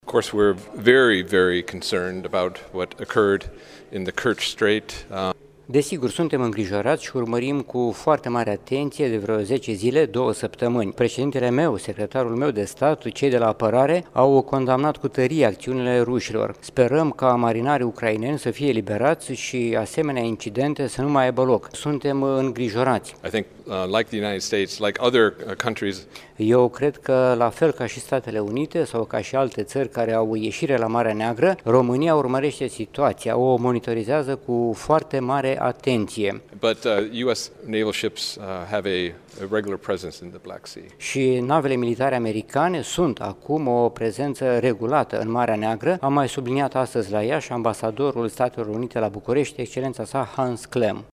Ambasadorul Americii la Bucureşti, Excelenţa Sa Hans Klemm a declarat, astăzi, în cadrul unei conferinţe la Universitatea Alexandru Ioan Cuza din Iaşi că Statele Unite şi conducerea de la Washington sunt îngrijorate de evoluţia conflictului de la Marea Neagră, dintre Ucraina şi Rusia.